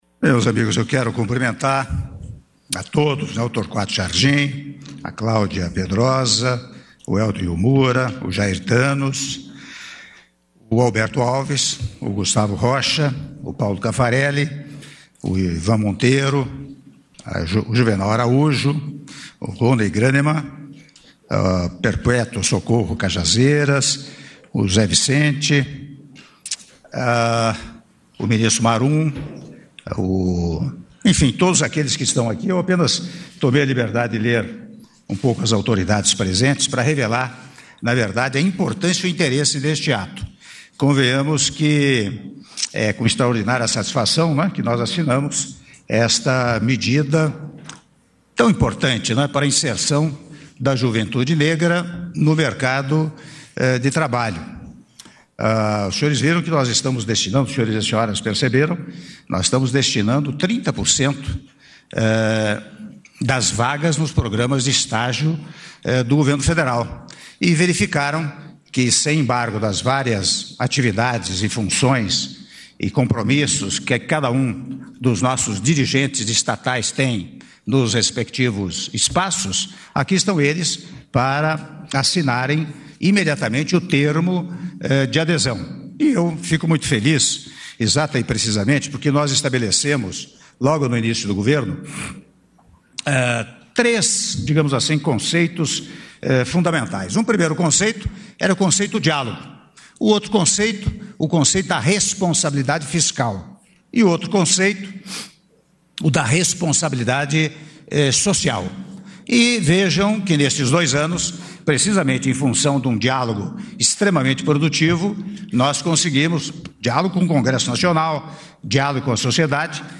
Áudio do discurso do Presidente da República, Michel Temer, durante Cerimônia de Assinatura do Decreto que Regulamenta Cotas em Vagas de Estágio para Jovens Negros, na Administração Pública -Brasília/DF- (07min09s)